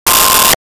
Sci Fi Beep 06
Sci Fi Beep 06.wav